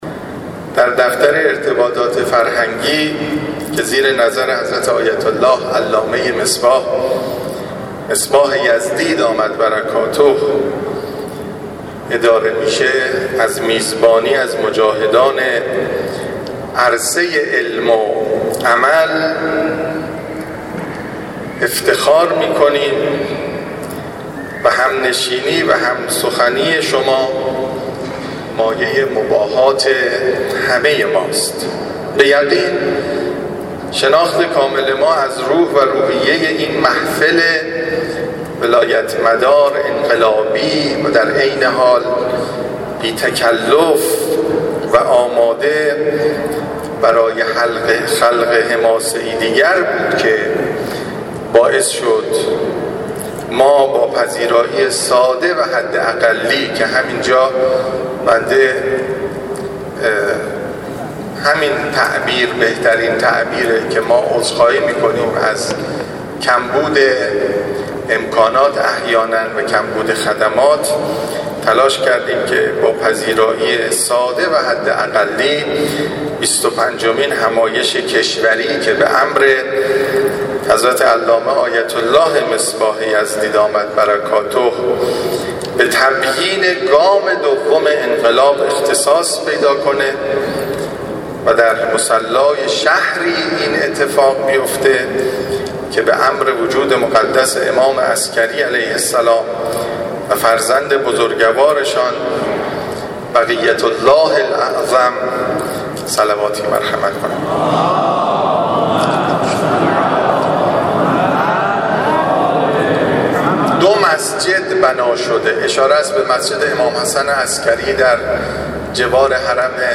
عصر امروز در همایش تبیین بیانیه گام دوم انقلاب و الزامات آن که در مصلای قدس قم برگزار شد، با تسلیت سالروز شهادت امام حسن عسکری(ع